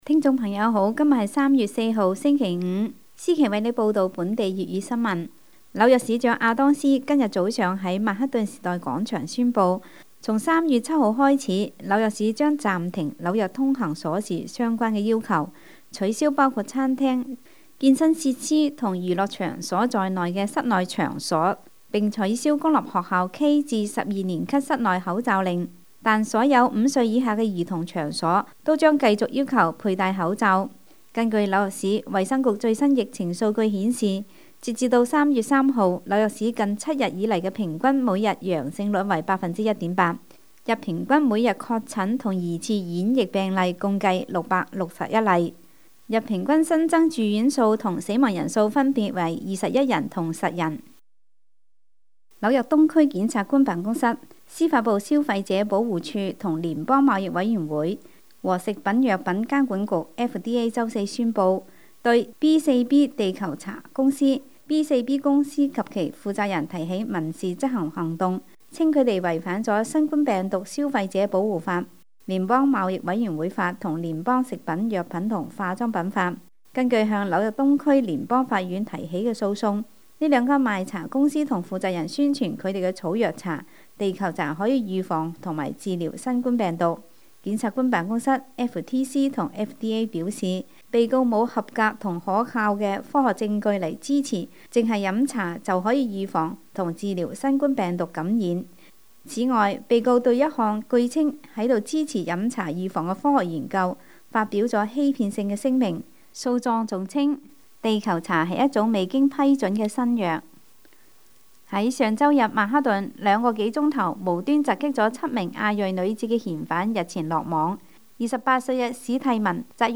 3月4日（星期五）纽约整点新闻